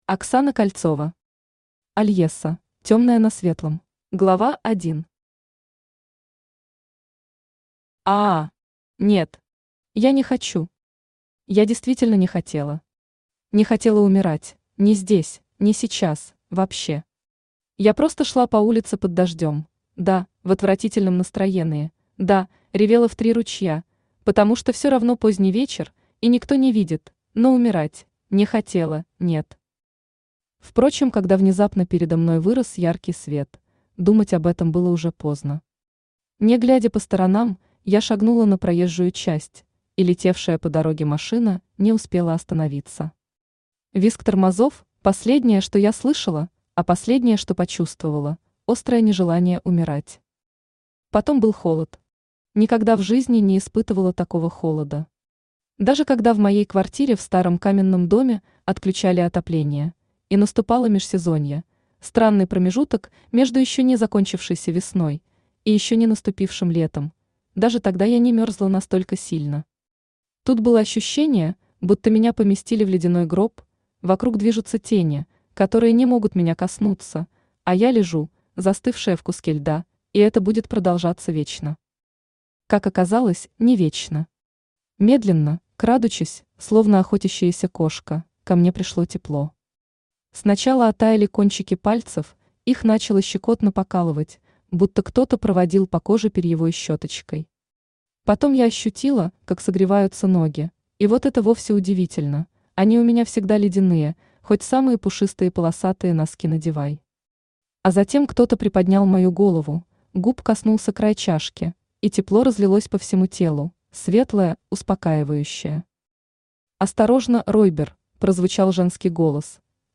Aудиокнига Альесса: Темное на светлом Автор Оксана Кольцова Читает аудиокнигу Авточтец ЛитРес.